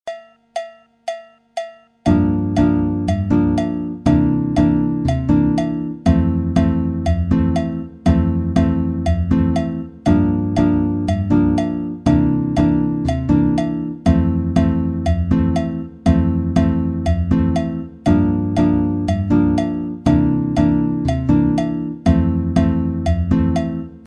Comme support de rythme j'ai mis un clic à la croche, c'est à dire 2 pulsations par temps et cela dans le but de bien décortiquer la tourne de guitare. Les accords sont Gm7, C79, F7 majeur et les mesures sont en 2/4 car la samba se vit sur 2 temps même si comme nous le verrons plus loin la rythmique est souvent sur 2 cellules à 2 temps.
La batida de base